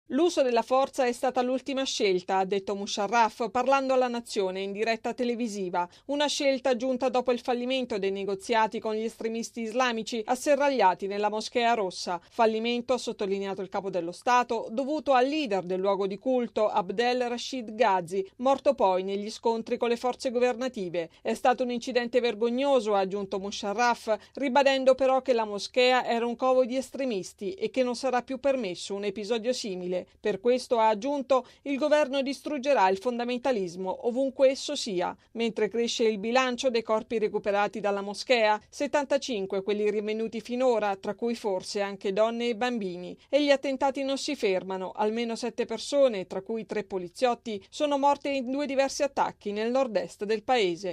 Questo in sintesi il discorso televisivo che il presidente pachistano Musharraf ha rivolto ieri alla Nazione, a conclusione della crisi alla Moschea Rossa di Islamabad. Il servizio